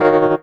17RHODS01 -R.wav